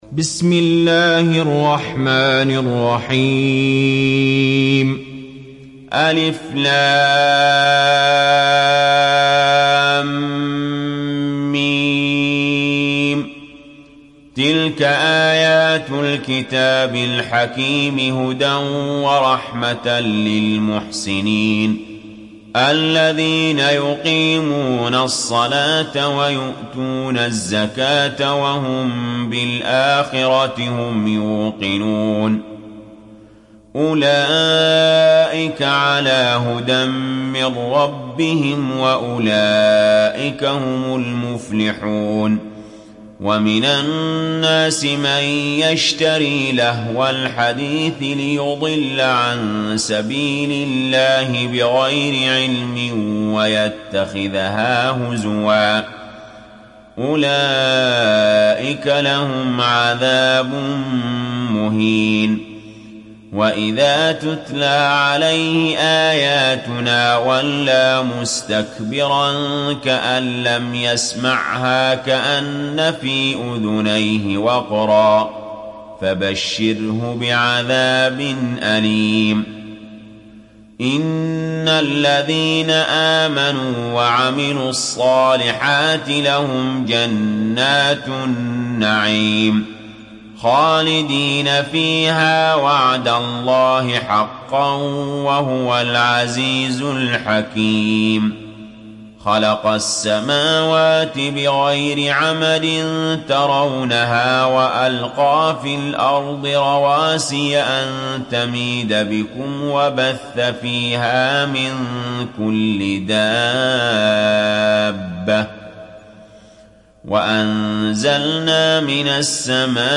تحميل سورة لقمان mp3 بصوت علي جابر برواية حفص عن عاصم, تحميل استماع القرآن الكريم على الجوال mp3 كاملا بروابط مباشرة وسريعة